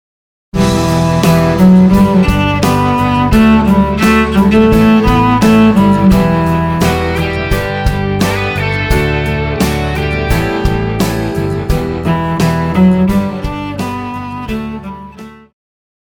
Pop
Cello
Band
Instrumental
Punk
Only backing